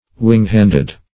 Search Result for " wing-handed" : The Collaborative International Dictionary of English v.0.48: Wing-handed \Wing"-hand`ed\, a. (Zool.) Having the anterior limbs or hands adapted for flight, as the bats and pterodactyls.